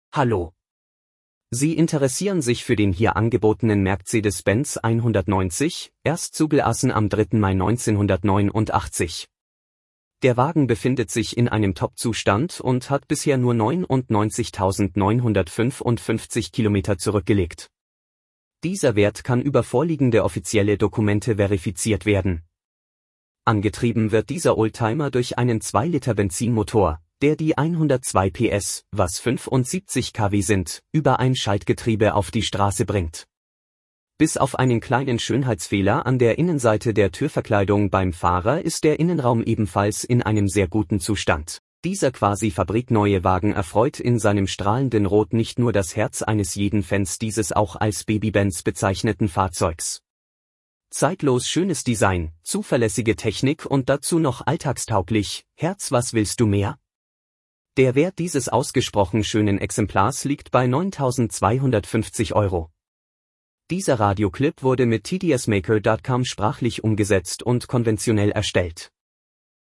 VerkaufsRadioClip_Mercedes190_rot_rostfrei-H.mp3